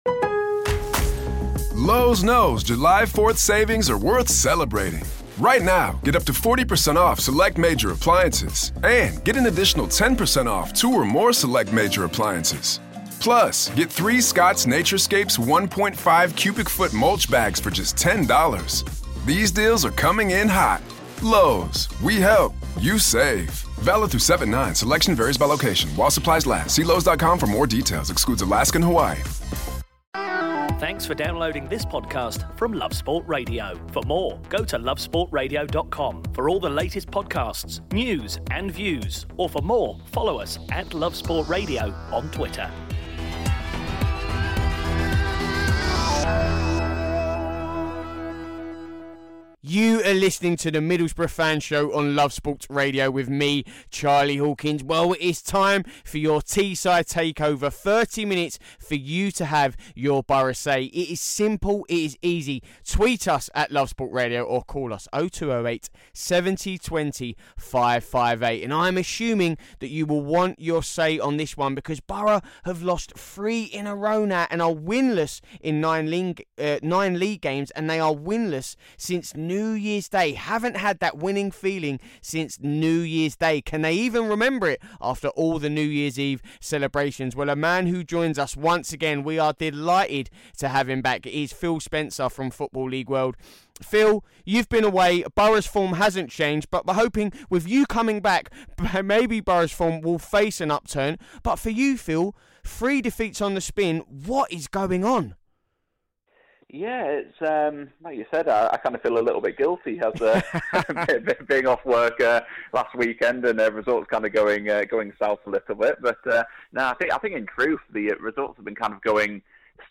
for a chat after the 1-0 loss at home to Leed United